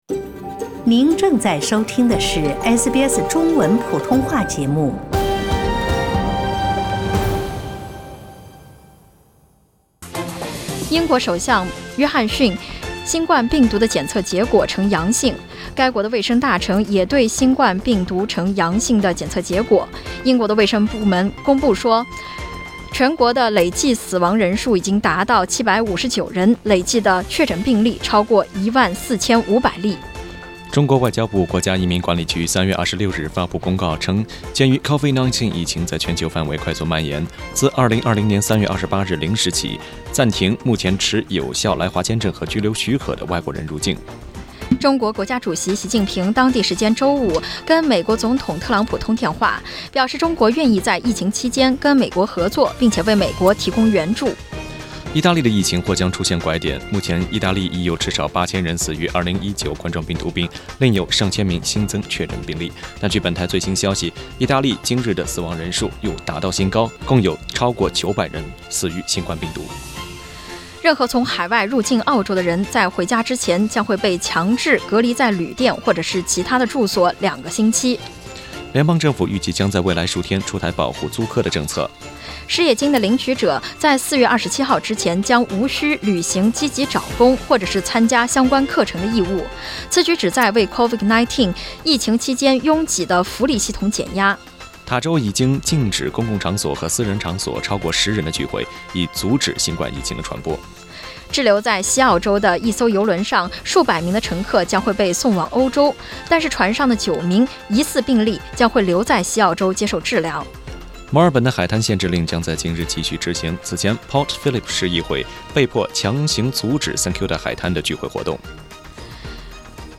SBS早新闻（3月28日）